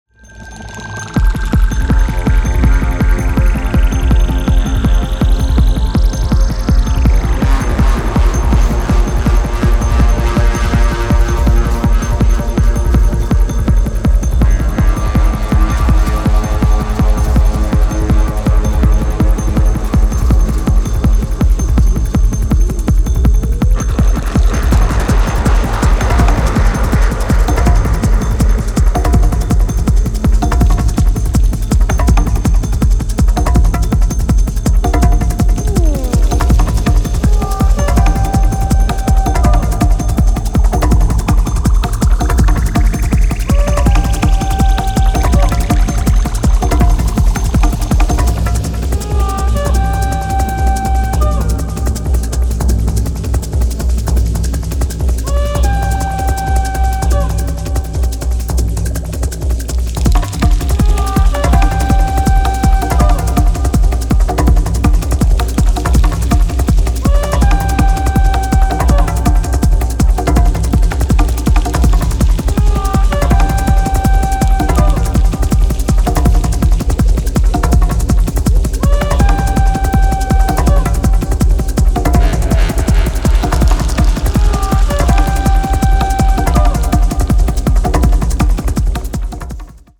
初期のスローモーなグルーヴからは装いを変えて、今回は速いです。
持ち味のトライバルなパーカッションも相まって非常にグルーヴィー、強力無比です。